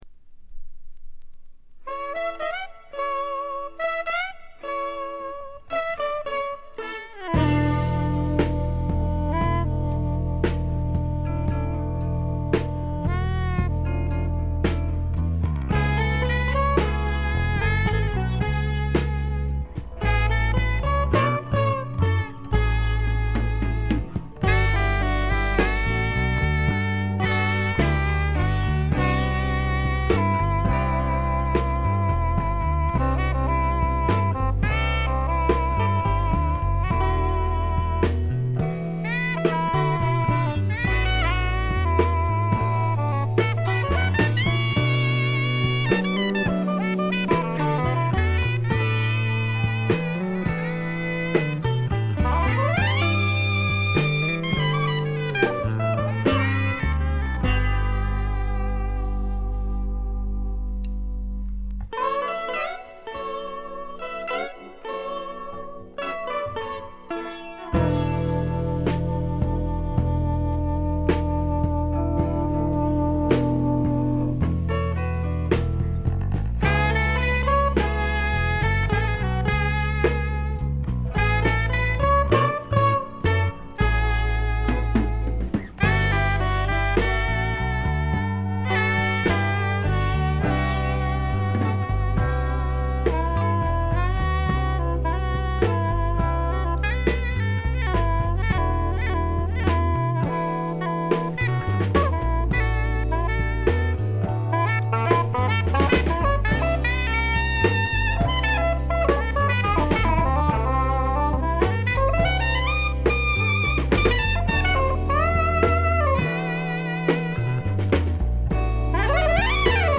ファンキーでジャズっぽい面もあるサウンドが心地よくくせになります。
試聴曲は現品からの取り込み音源です。